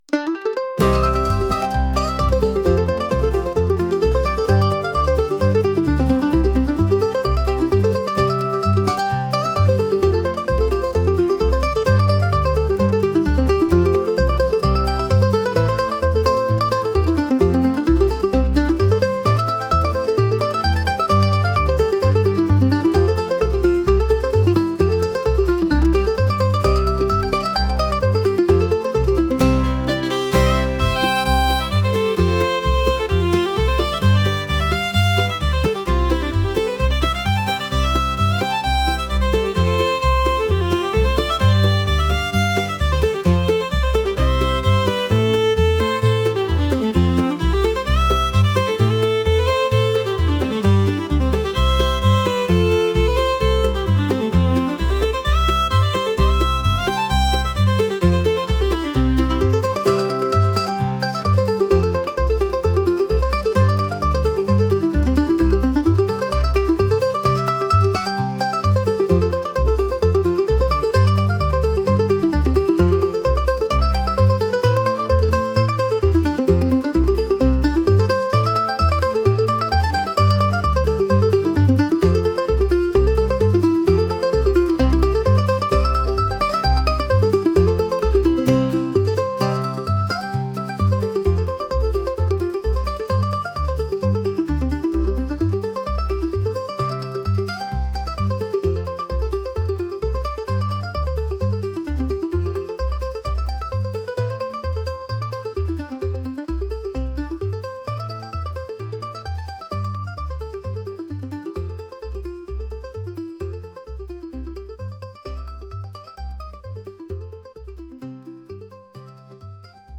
country | folk | acoustic